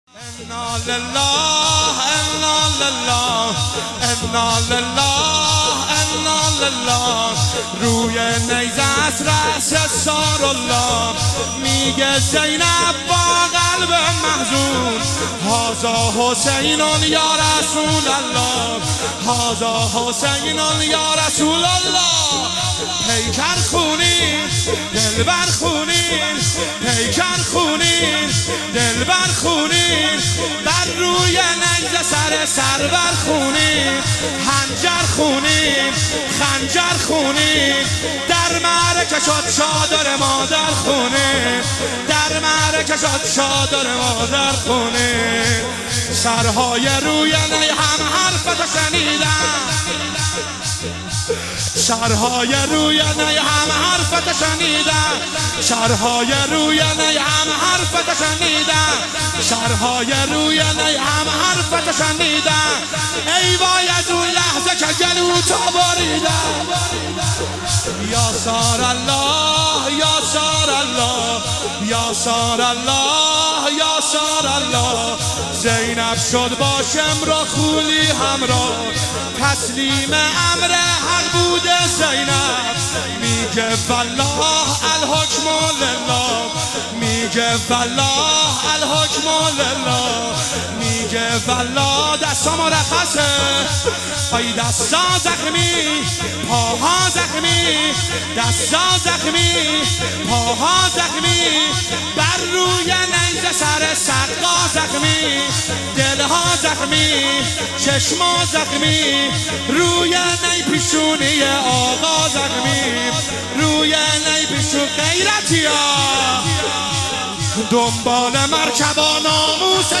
ایام فاطمیه 1441 | هیئت جنت العباس (ع) قم